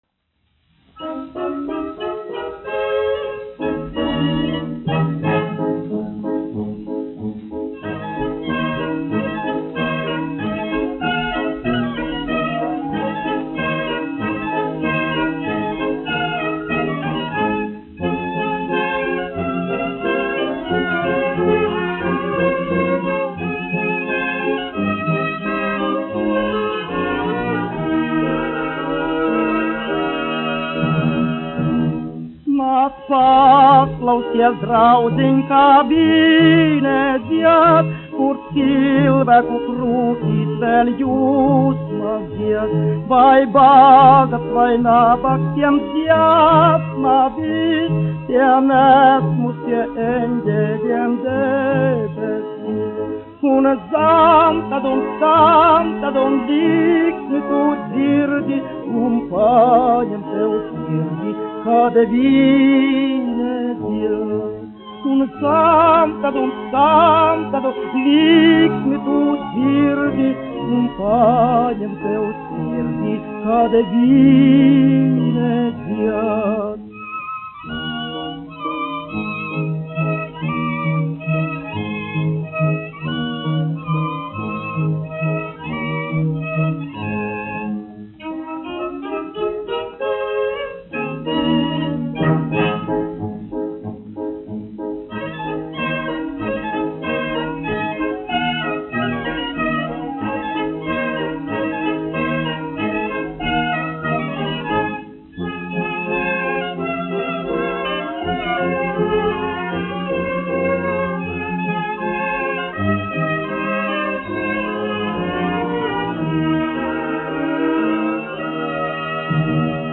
1 skpl. : analogs, 78 apgr/min, mono ; 25 cm
Operetes--Fragmenti
Latvijas vēsturiskie šellaka skaņuplašu ieraksti (Kolekcija)